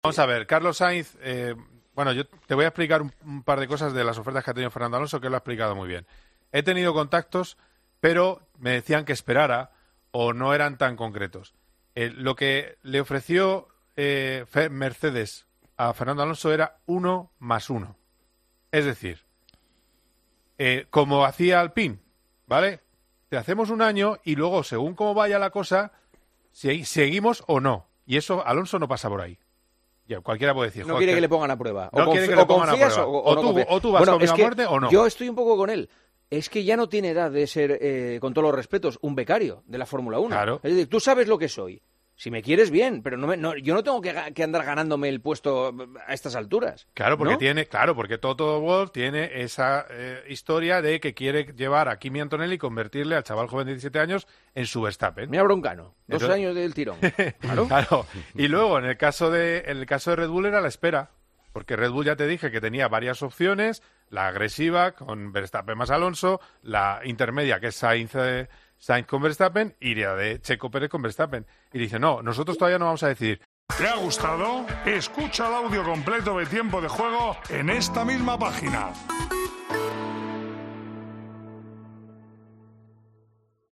Juanma Castaño se mostraba totalmente de acuerdo con el punto de vista de Fernando Alonso y decía: "Entiendo a Fernando. Ya no tiene edad para ser un becario de la Fórmula 1, los equipos ya saben quién es y no debe ganarse el puesto a estas alturas de su carrera profesional".